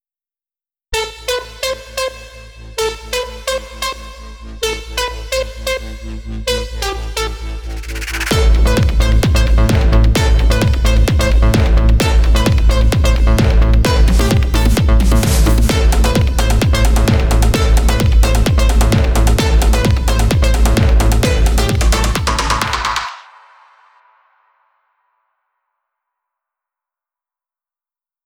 הסאונדים מכל מיני חבילות הקיק זה פריסט של הפלאגין קיק 3